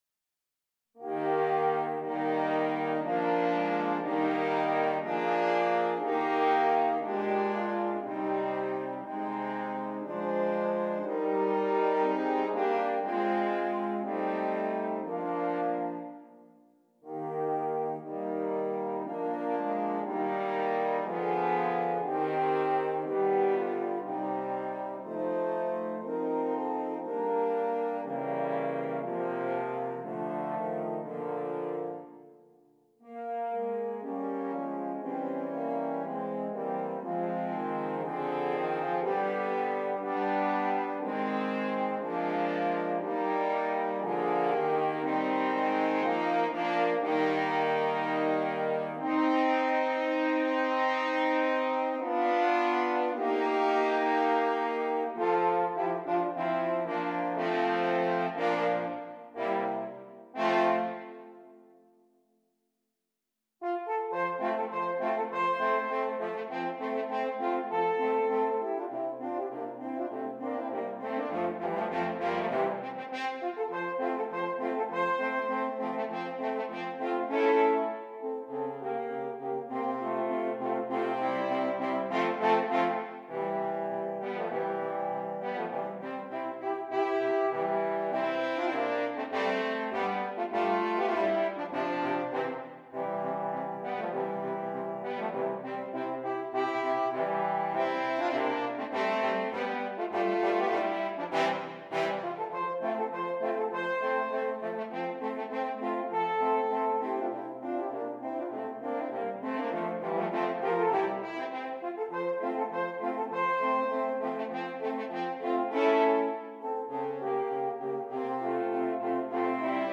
4 F Horns
horn quartet